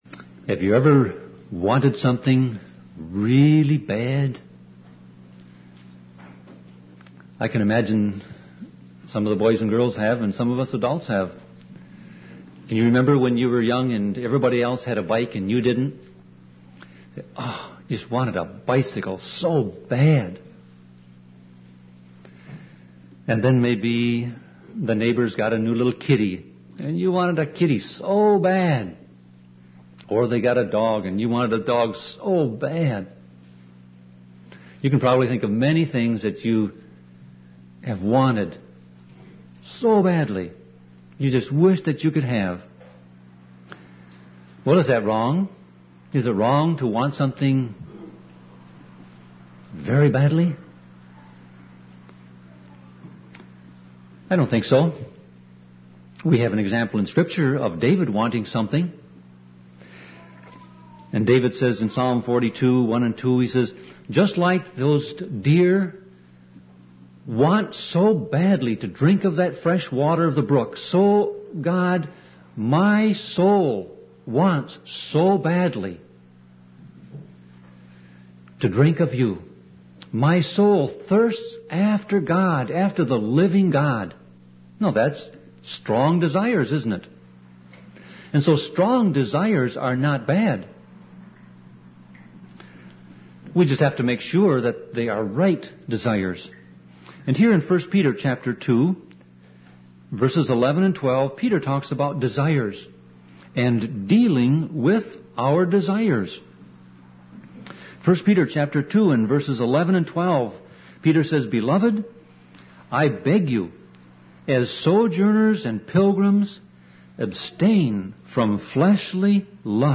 Sermon Audio Passage: 1 Peter 2:11-12 Service Type